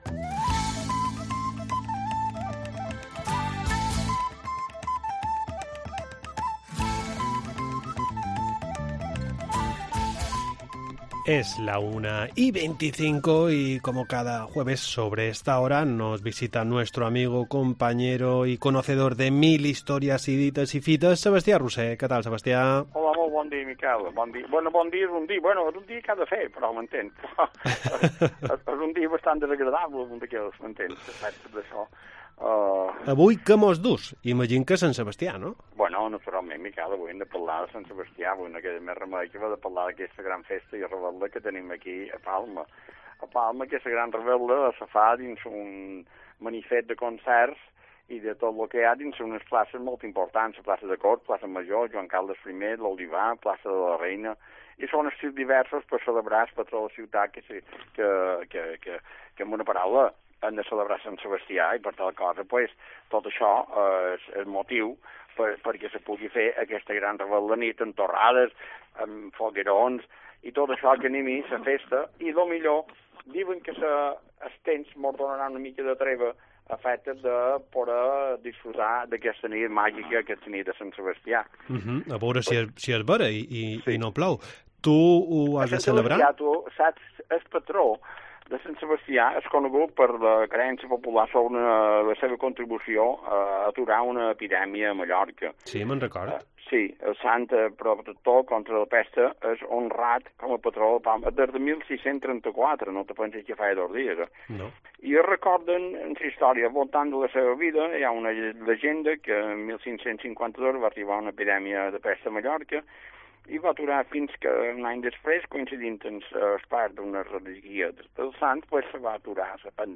Espacio semanal donde repasamos las costumbres mallorquinas y os contamos las ferias y fiestas de los próximos días. Entrevista en 'La Mañana en COPE Más Mallorca', jueves 19 de enero de 2023.